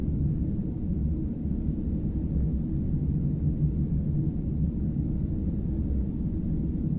galley.ogg